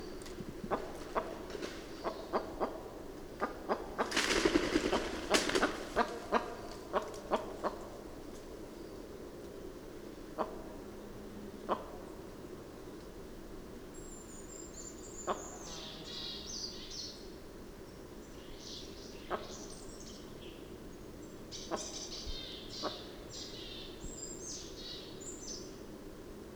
Голос самки глухаря звучит